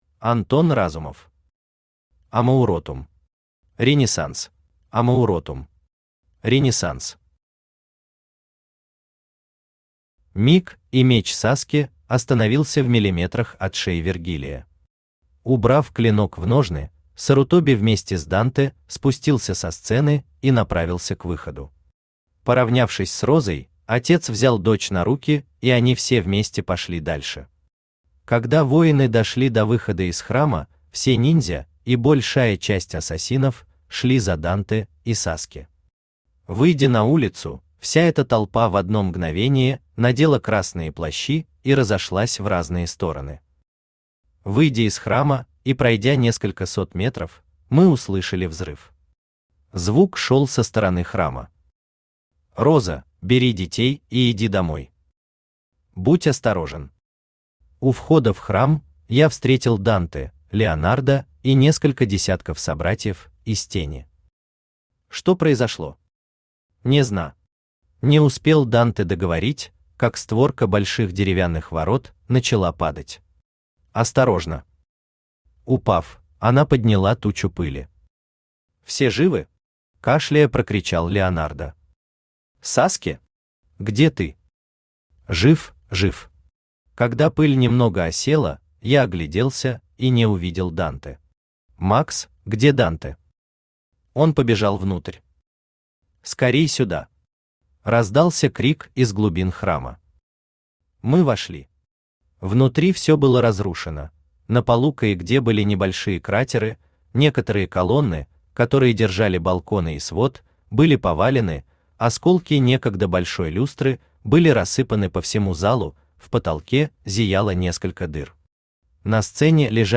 Аудиокнига Амауротум. Ренессанс | Библиотека аудиокниг
Ренессанс Автор Антон Андреевич Разумов Читает аудиокнигу Авточтец ЛитРес.